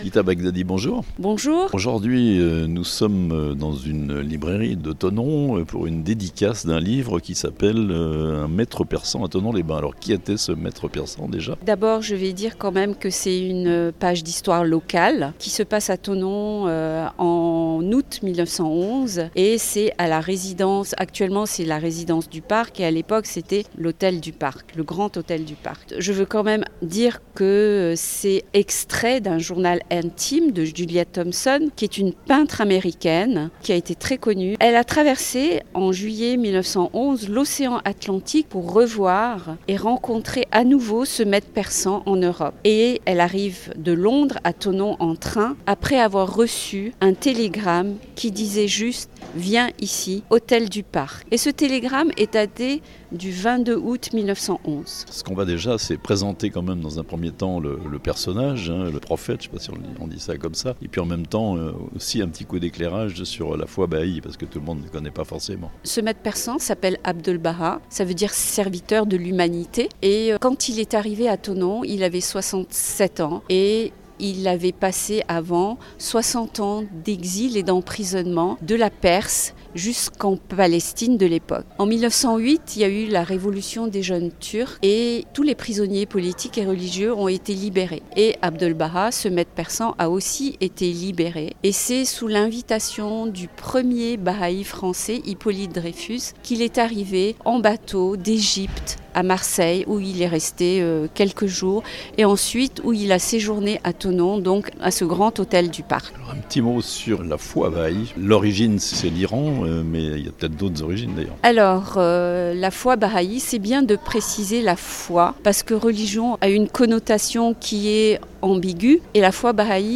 L'un des fondateurs de la foi baha'ie a fait un court séjour à Thonon en août 1911 (interview)